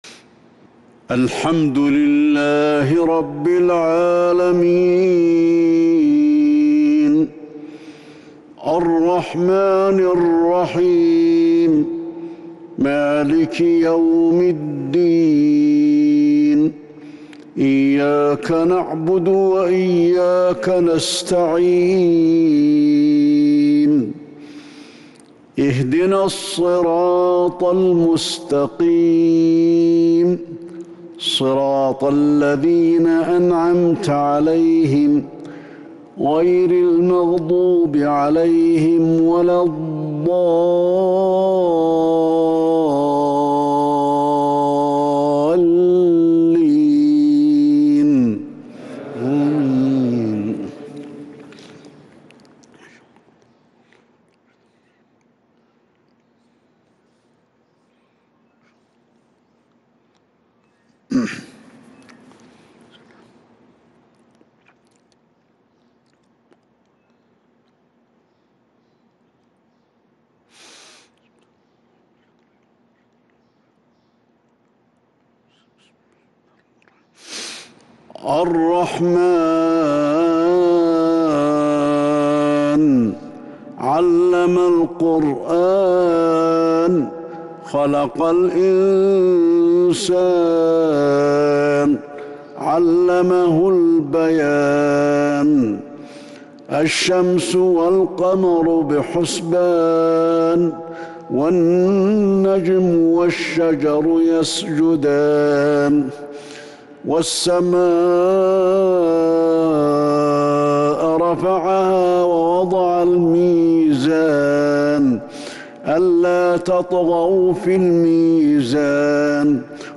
صلاة العشاء الشيخ ياسر الدوسري